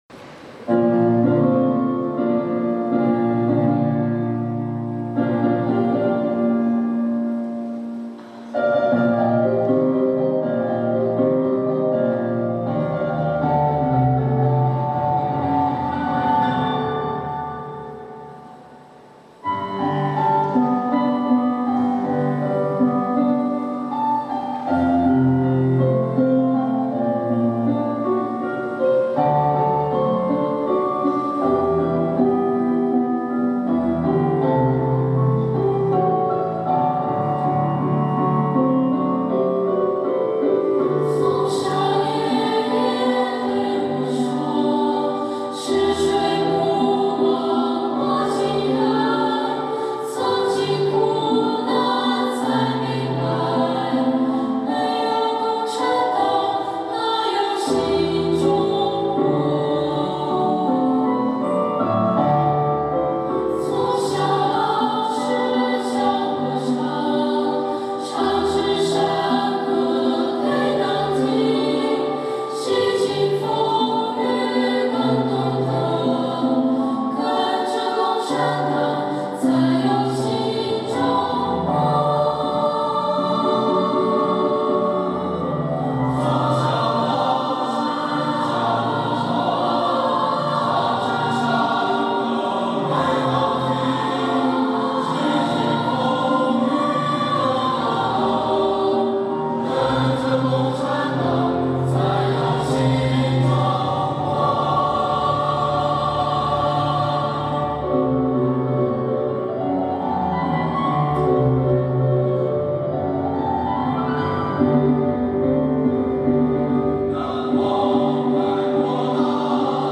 《在灿烂阳光下》全曲分为三段，以A-B-A1的形式呈现。